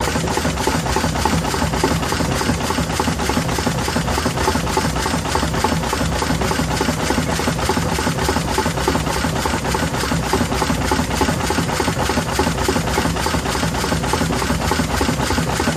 Steam Motor, Loop Mid Section